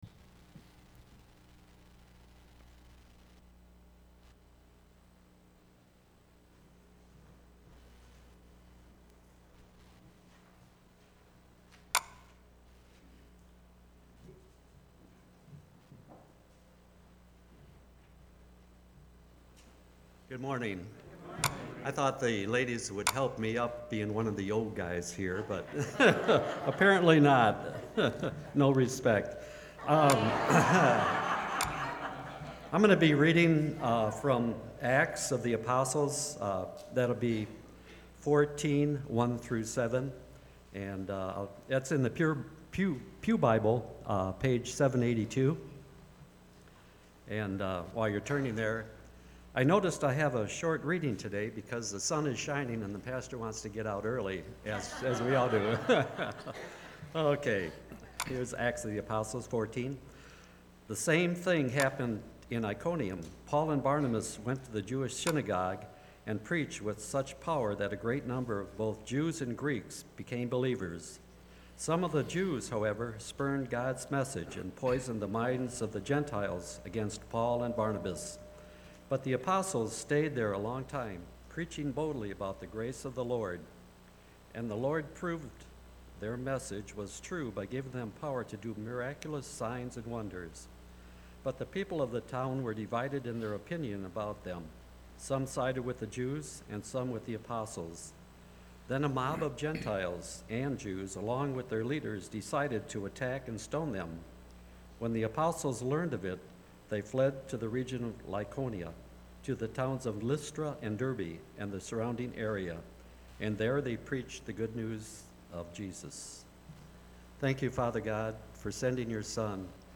A message from the series "Apologetics." Ever had a time in life when a situation changed rapidly?